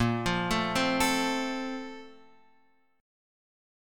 A# Major 7th Suspended 2nd